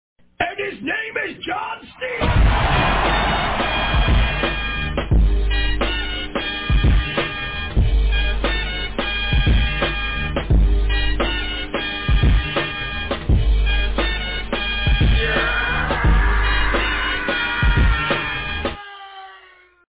and-his-name-is-john-cena-sound-effect-hd-1.mp3